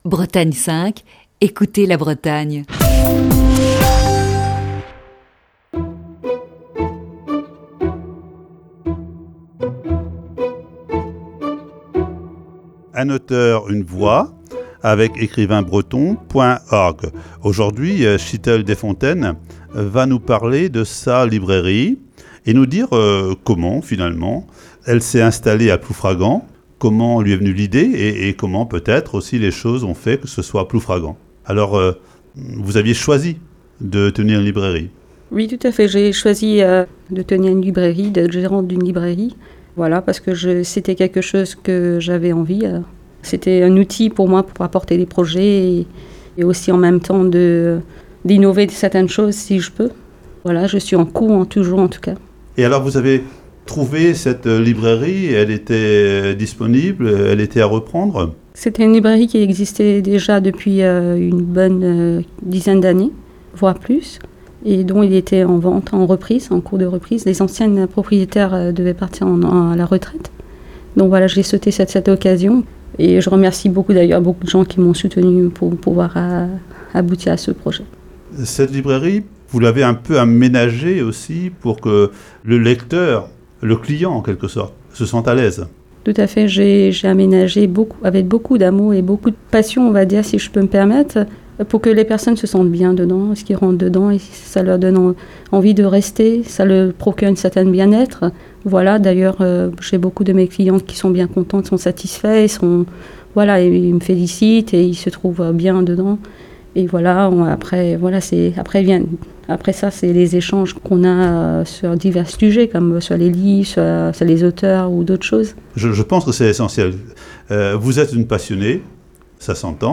Voici ce mercredi, la troisième partie de cet entretien.